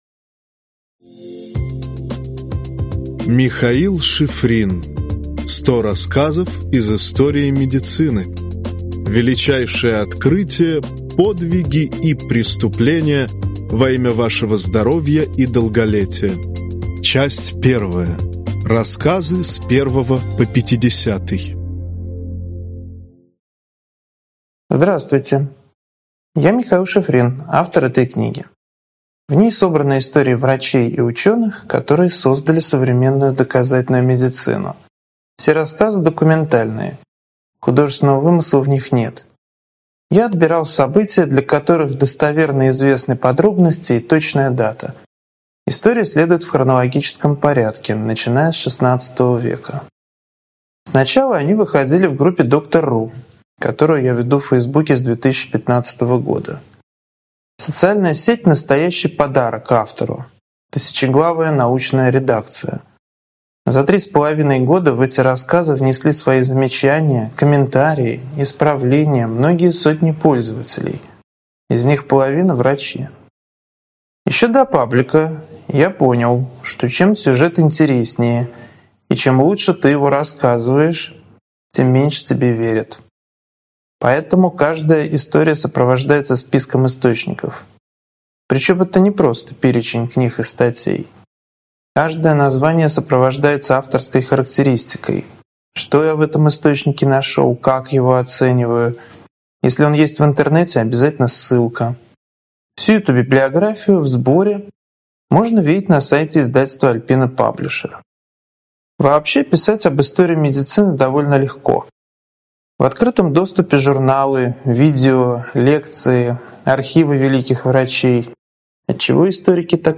Аудиокнига 100 рассказов из истории медицины. Часть 1 (рассказы с 1 по 50) | Библиотека аудиокниг